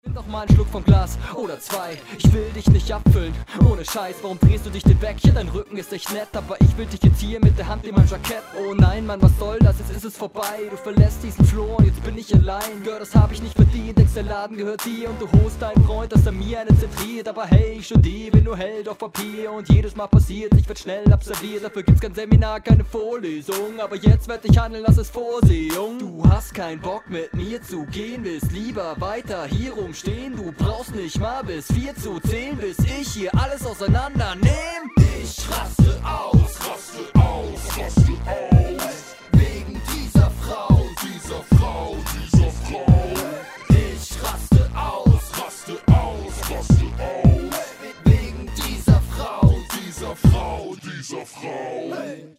rough gemixter Ausschnitt
Jetzt sind wir also eine richtige Studentenrapper-Crew.